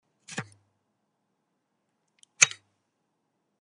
Звуки подключения, отключения USB
На этой странице собрана коллекция звуков, которые издает компьютер при подключении и отключении USB-устройств.